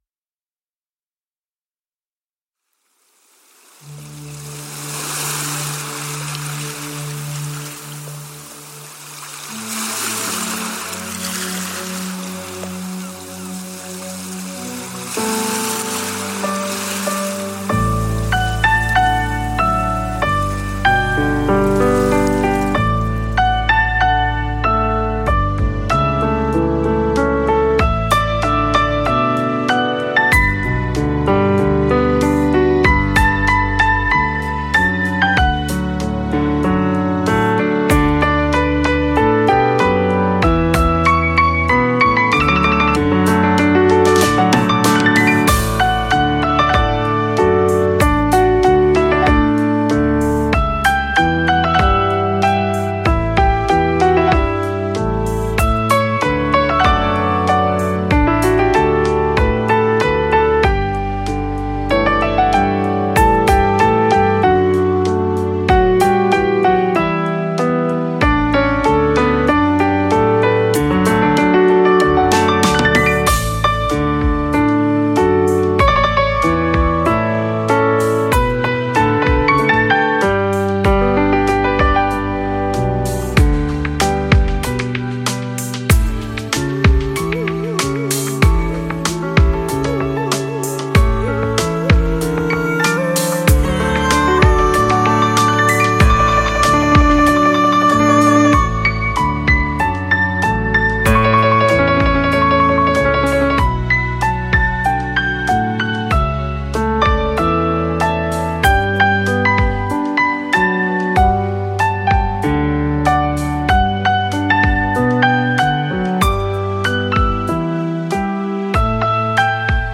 آهنگهای پاپ فارسی
بی کلام